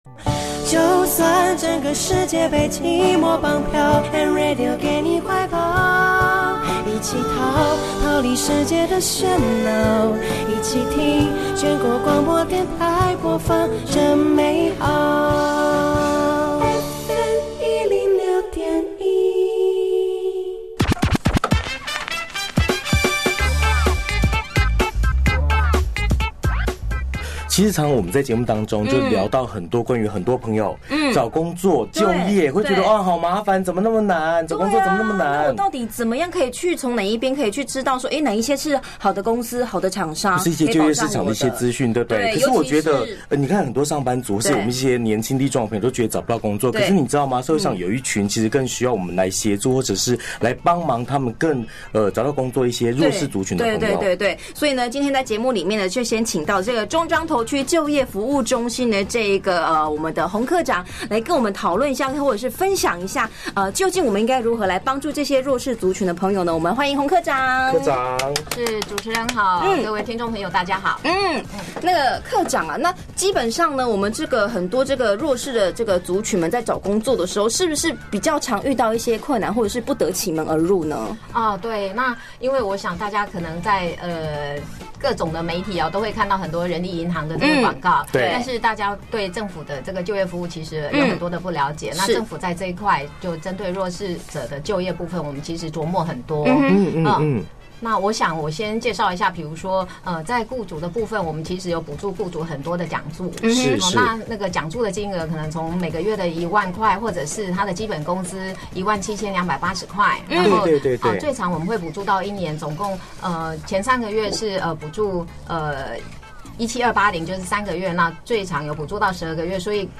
990902 公司榮獲職訓局中彰投區就業服務中心-2010就業公義獎楷模全國廣播受訪(
現場錄音)